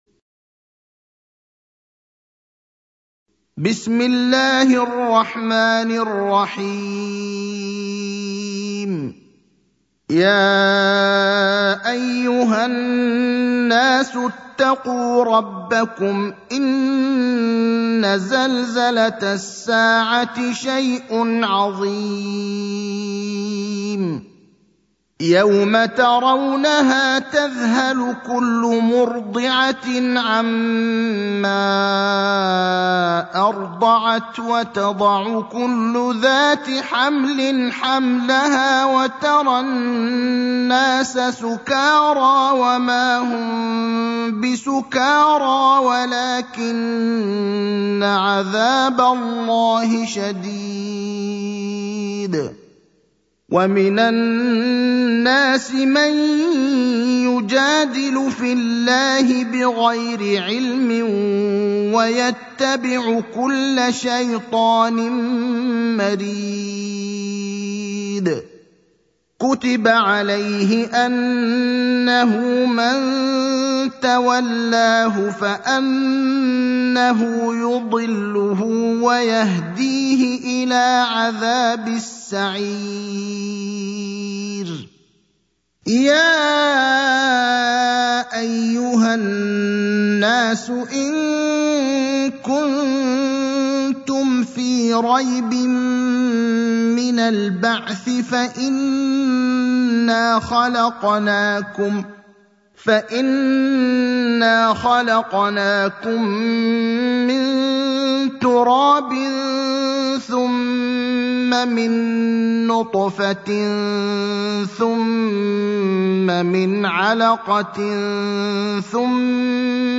المكان: المسجد النبوي الشيخ: فضيلة الشيخ إبراهيم الأخضر فضيلة الشيخ إبراهيم الأخضر الحج (22)